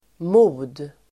Uttal: [mo:d]